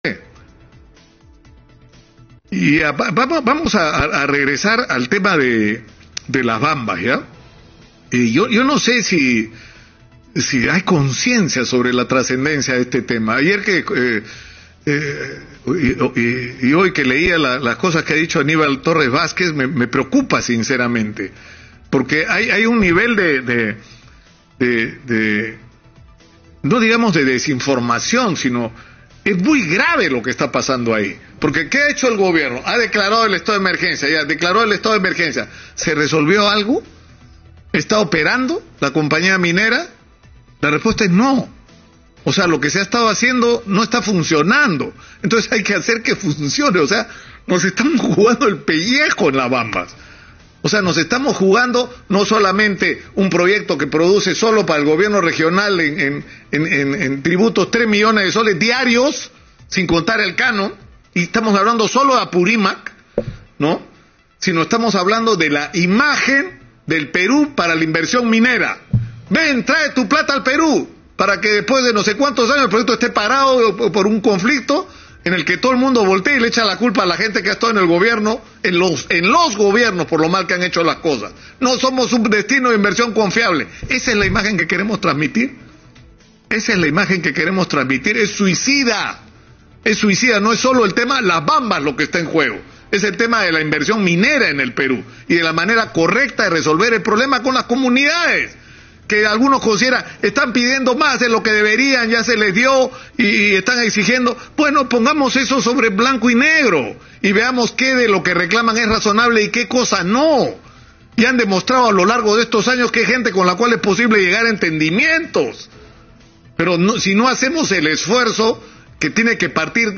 Con respecto al tema de Las Bambas, el periodista Nicolás Lúcar, dijo que la inversión minera en este proyecto es muy importante y aseguró que se debe resolver este problema de manera correcta con las comunidades de la región.